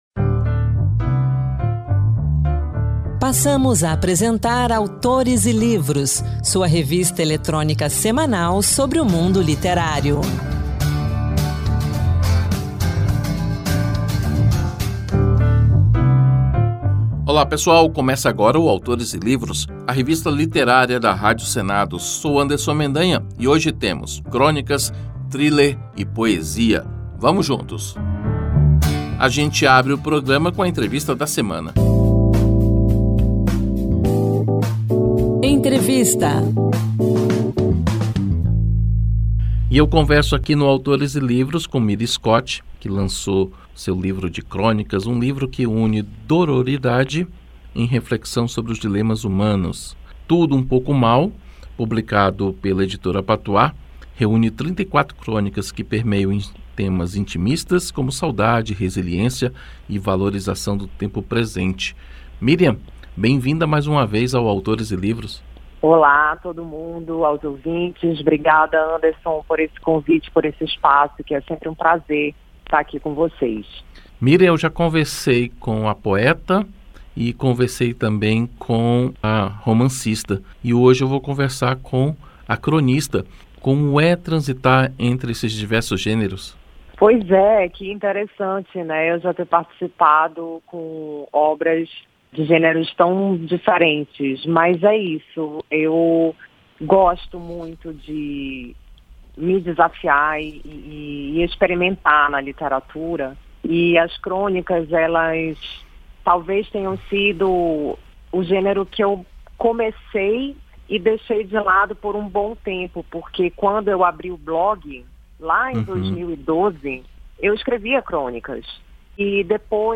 O Autores e Livros desta semana traz uma conversa